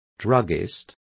Complete with pronunciation of the translation of druggists.